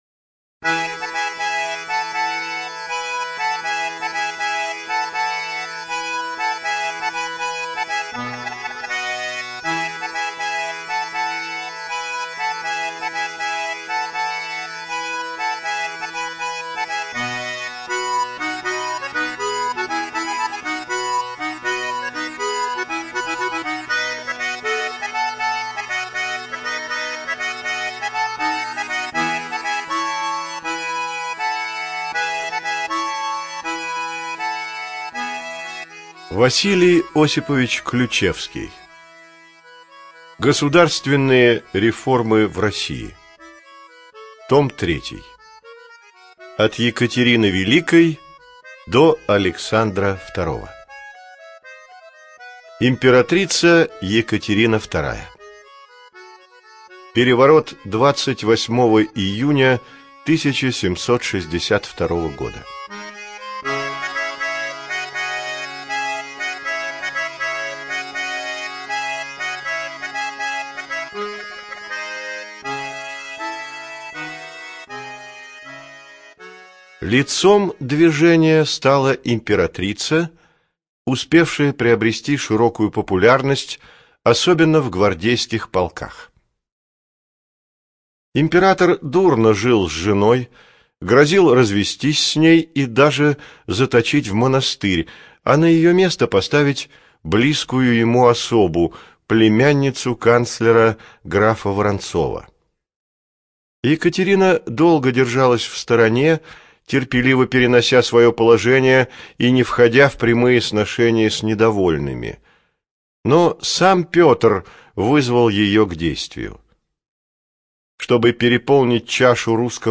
Информация о книге Государственные реформы в России - 03 (Библиотека ЛОГОС)